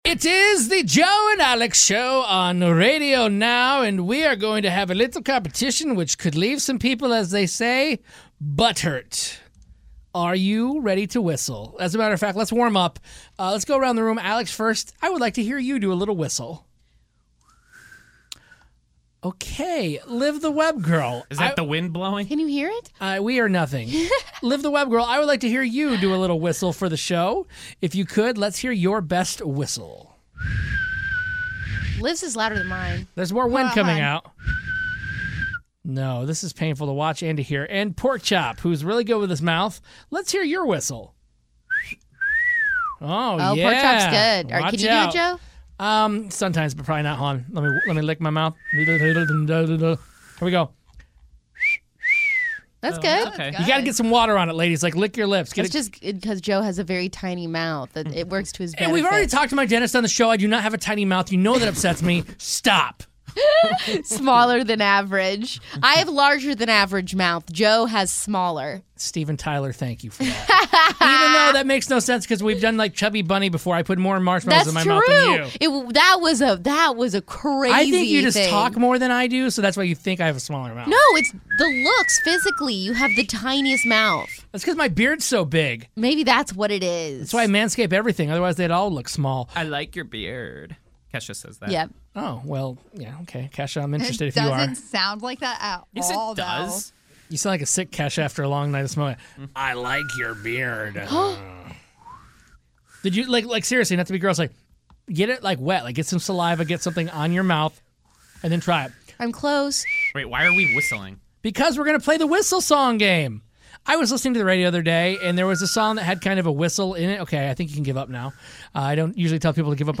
Do you know the artist of these popular songs that involve whistling?